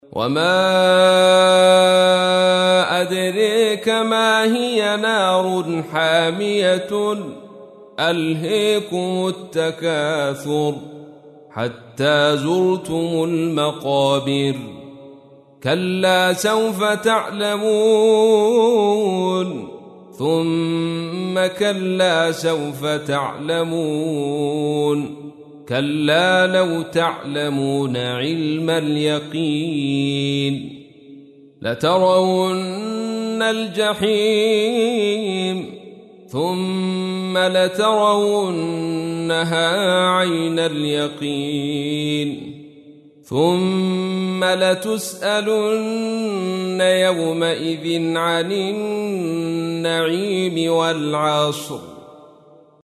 تحميل : 102. سورة التكاثر / القارئ عبد الرشيد صوفي / القرآن الكريم / موقع يا حسين